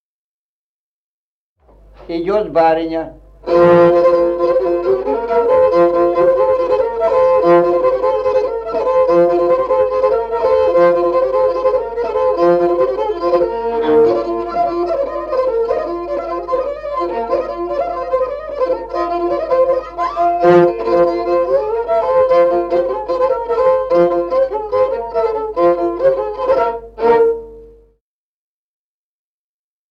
Музыкальный фольклор села Мишковка «Барыня», репертуар скрипача.